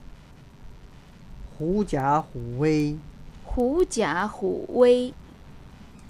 発音 mp3
发　音:hú jiǎ hǔ wēi